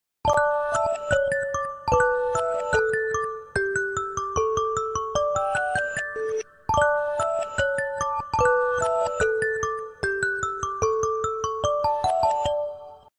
I just hate this alarm sound effects free download